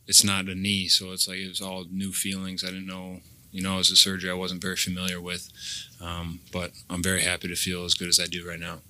He was a full participant yesterday, and said that he feels good, and is hopeful he can play on Sunday.  At a news conference yesterday, Watt said that the tough part to get back to playing shape was how to go about recovering from a punctured lung.